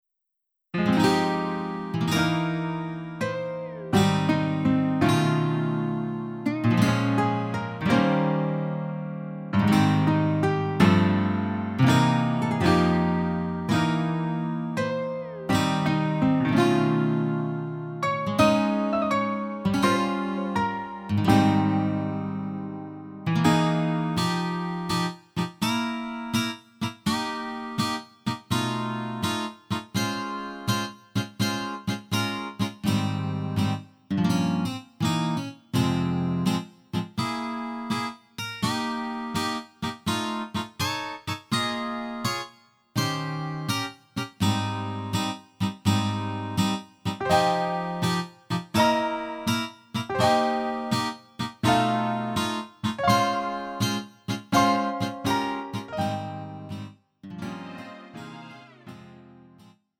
음정 원키 3:33
장르 가요 구분